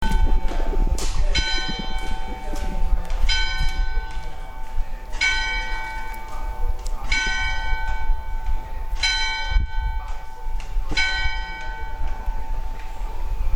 Ore-Sette.mp3